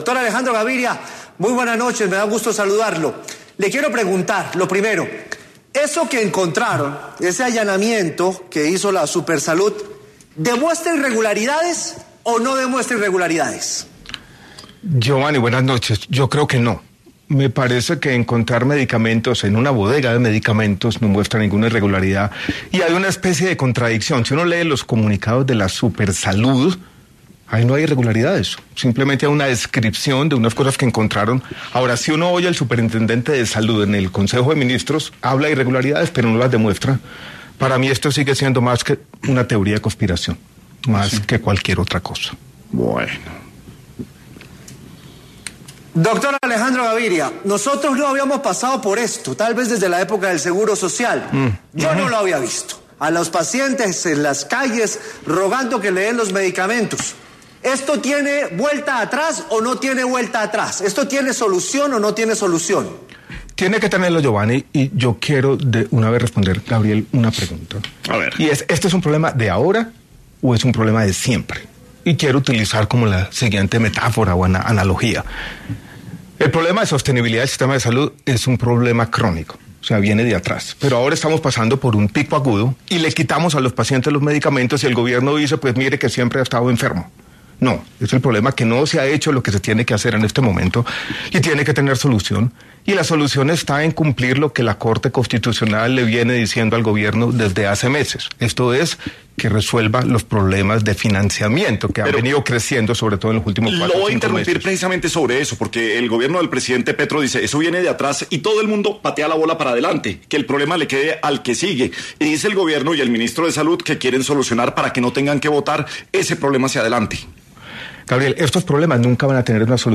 El exministro Gaviria estuvo tras los micrófonos de La Luciérnaga y abordó los problemas actuales del sistema de salud.
En una coyuntura en la que el ministro de Salud, Guillermo Jaramillo, presentó una denuncia penal contra directivos de Audifarma por presunto acaparamiento de medicamentos, el exministro, Alejandro Gaviria, pasó por los micrófonos de ‘Sin Anestesia’ para abordar esta situación.